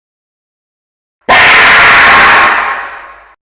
chibi chipi pachachapa Meme Sound Effect